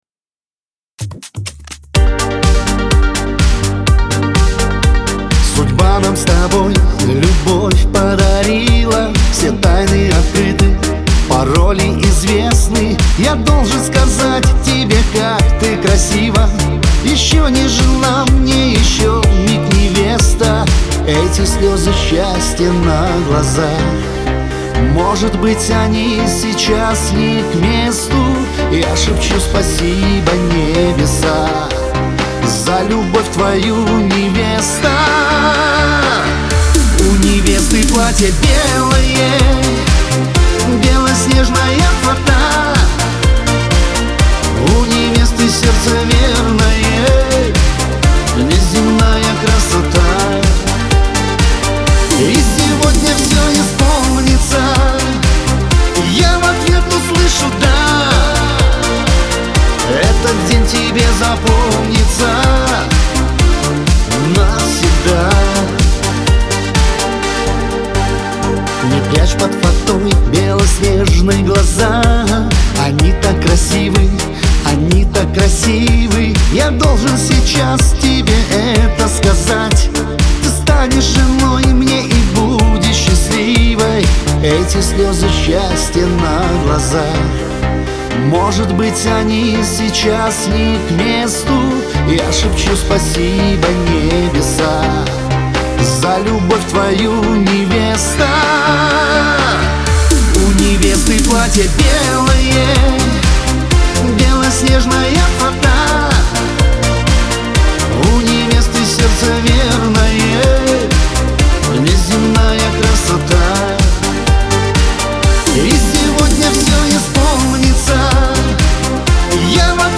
pop , эстрада